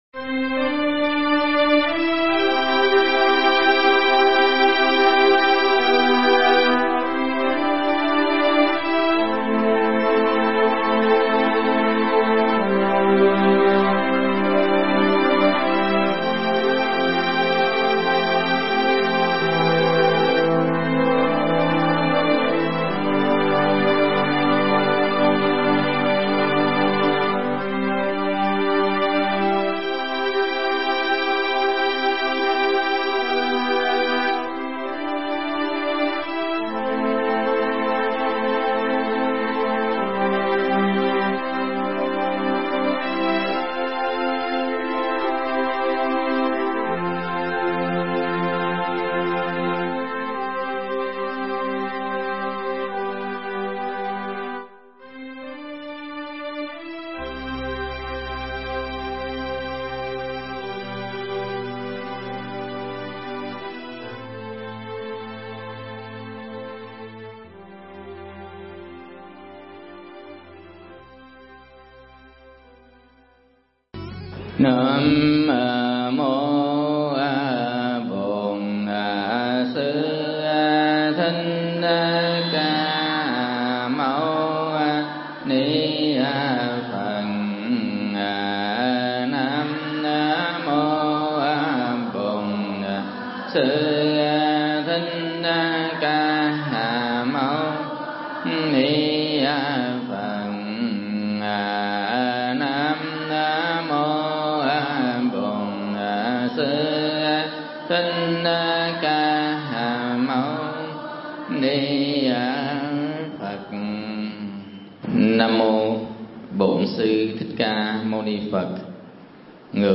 Mp3 Pháp Thoại Báo Hiếu Một Cách Thiết Thực
thuyết giảng tại Vô Ưu Tự, Quận Tân Bình